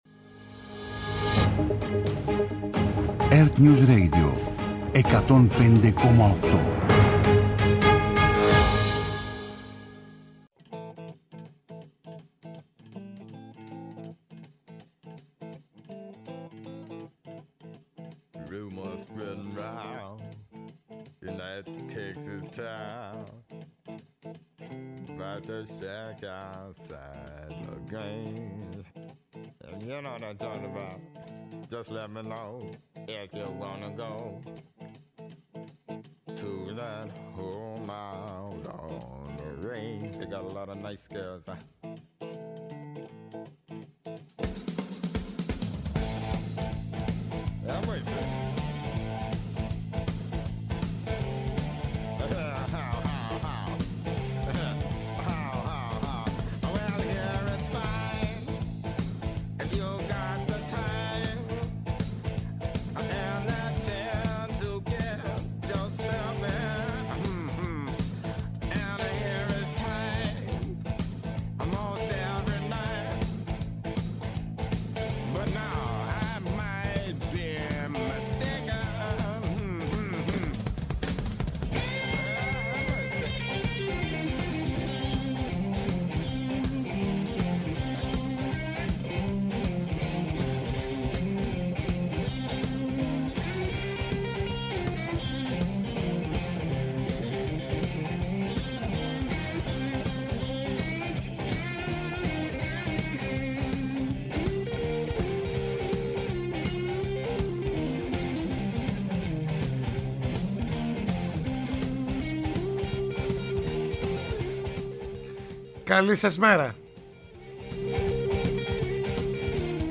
-Ο Αλέξης Χαρίτσης, Πρόεδρος Νέας Αριστεράς